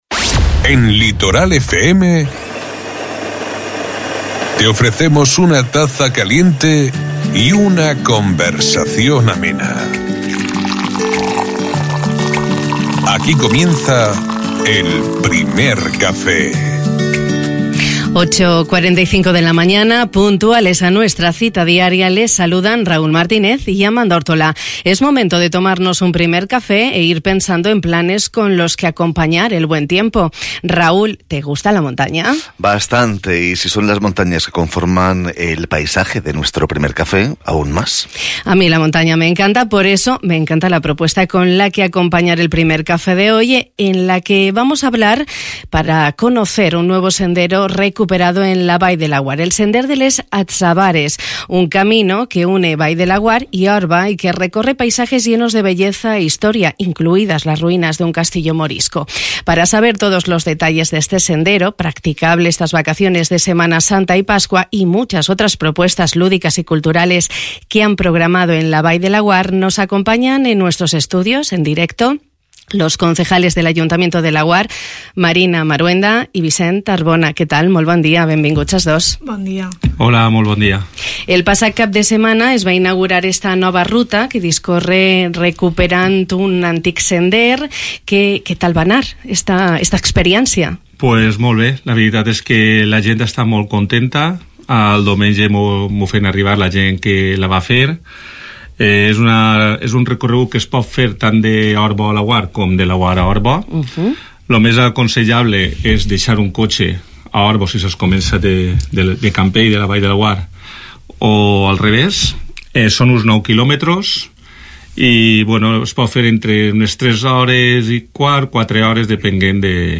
Nos lo han contado los ediles del Ayuntamiento de Laguar, Marina Marhuenda y Vicent Arbona.